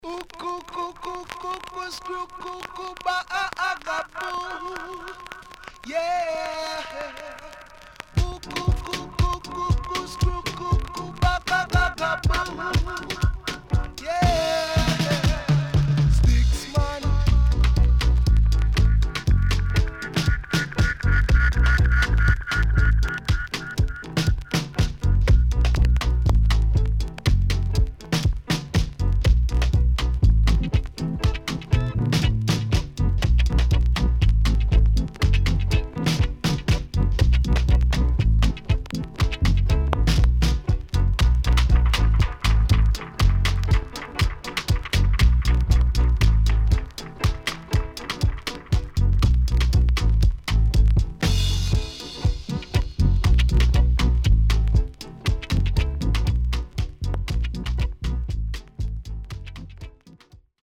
HOME > REGGAE / ROOTS  >  KILLER & DEEP
SIDE A:序盤ノイズ目立ちますが落ち着きます。所々チリノイズがあり、少しプチノイズ入ります。